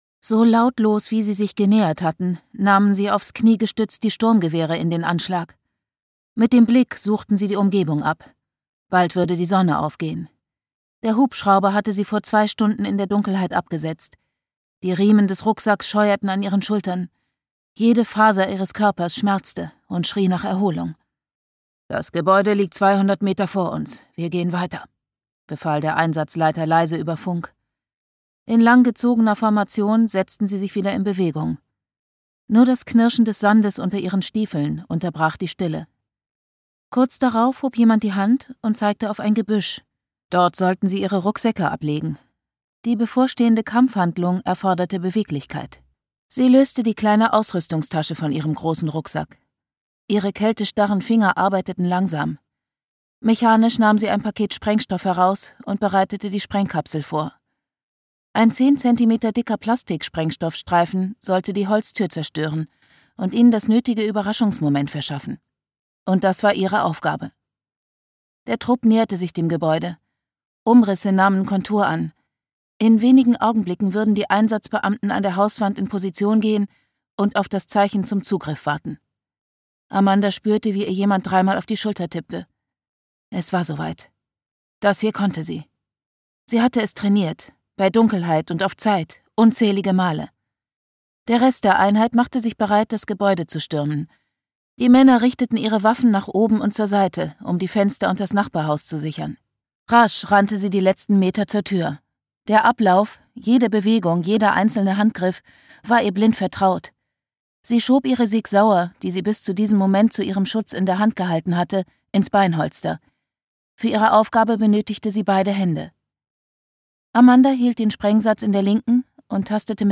2019 | Leicht gekürzte Lesung